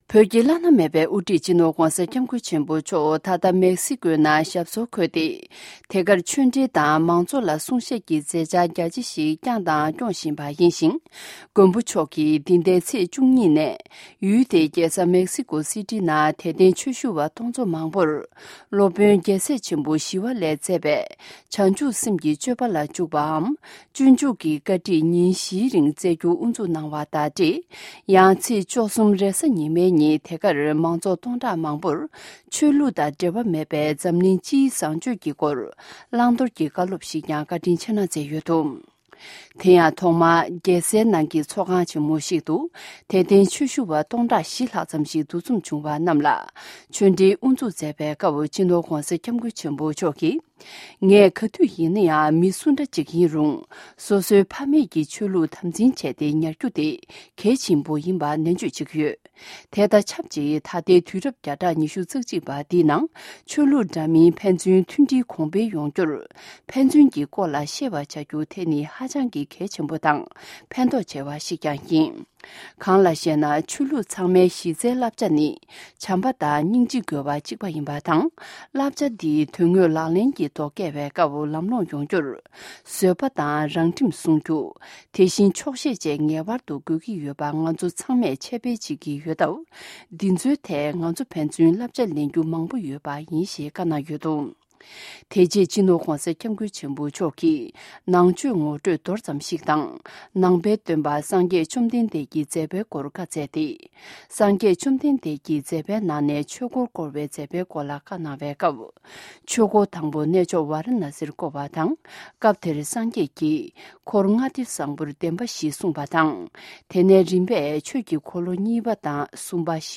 ༧གོང་ས་མཆོག་གིས་མེཀ་སི་ཀོའི་ནང་མང་ཚོགས་ལ་བཟང་སྤྱོད་ཀྱི་སྐོར་བཀའ་སློབ།